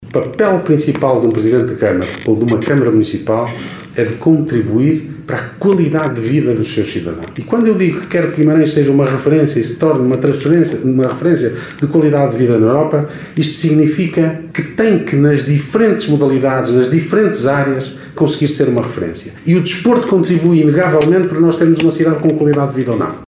Declarações de Ricardo Araújo, presidente da Câmara Municipal de Guimarães.